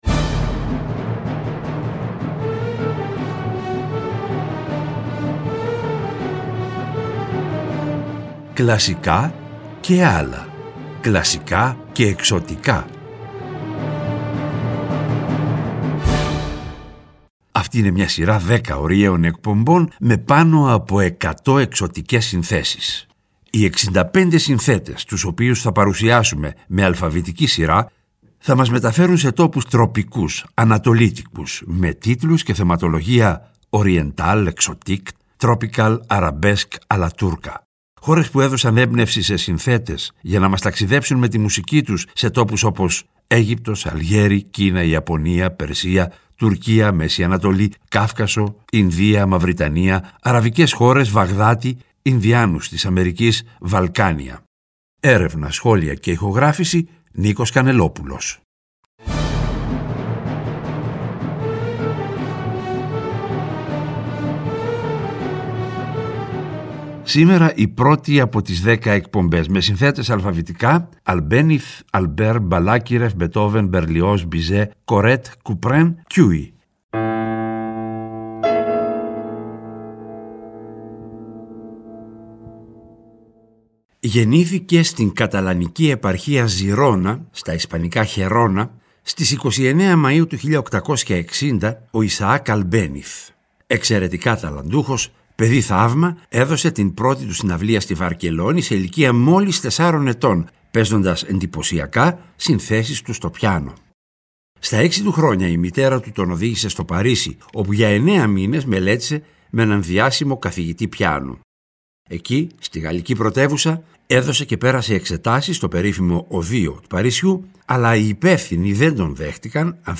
Τον Απρίλιο τα «Κλασικά και ..Άλλα» παίρνουν χρώμα Ανατολής και γίνονται «Κλασικά και …Εξωτικά», σε μια σειρά 10 ωριαίων εκπομπών, με πάνω από 100 εξωτικές συνθέσεις.